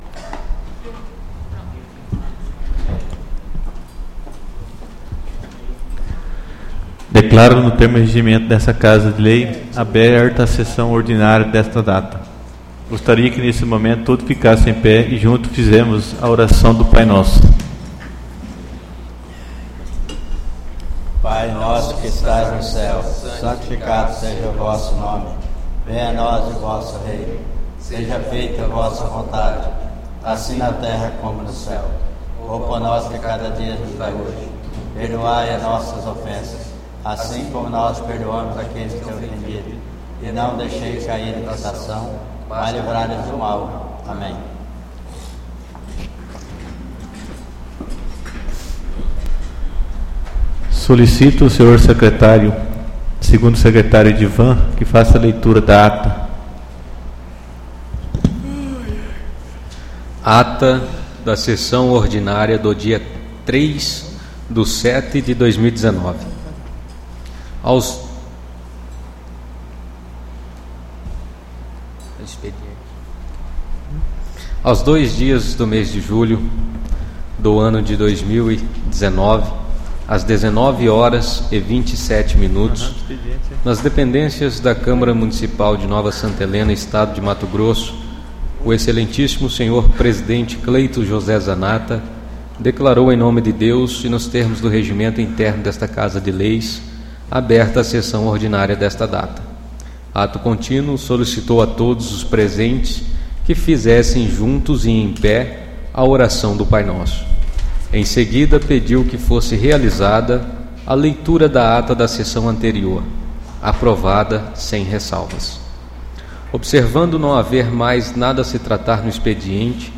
Áudio da Sessão Ordinária 09/07/2019